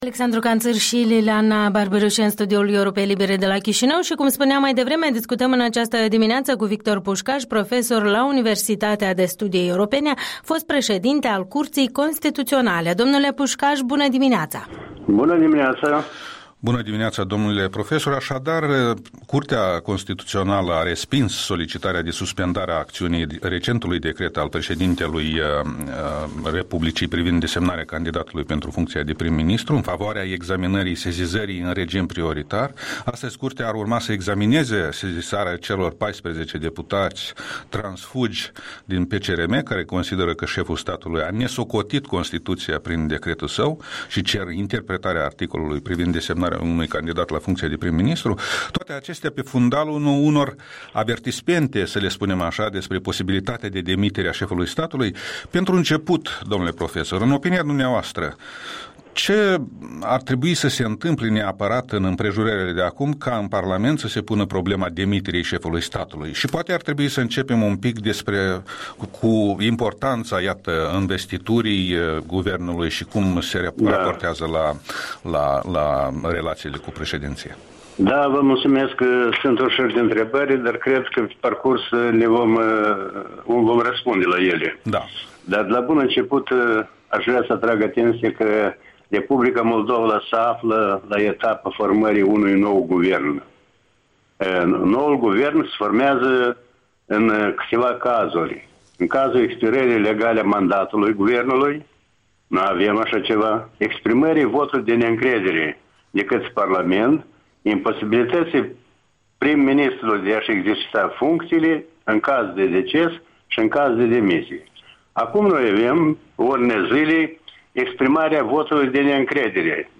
Interviul dimineții cu profesorul la Universitatea de Studii Europene, fost preşedinte al Curţii Constituţionale.